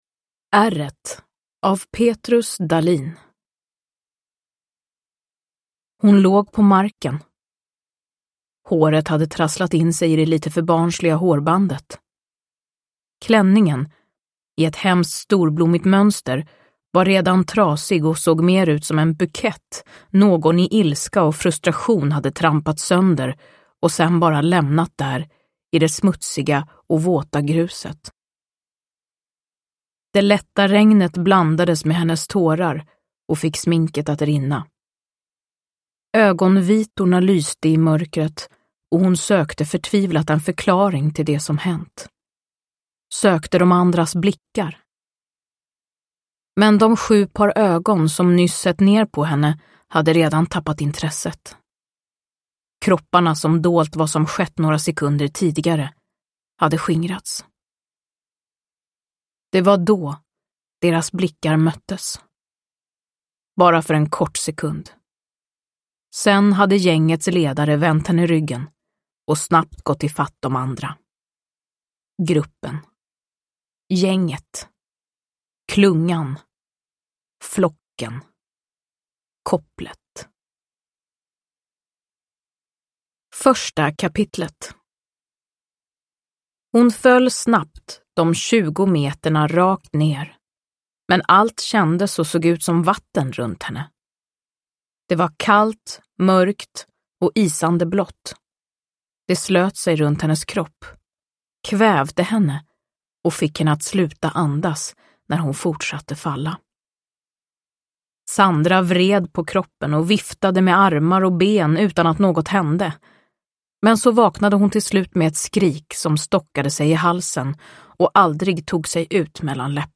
Ärret – Ljudbok – Laddas ner
Uppläsare: Frida Hallgren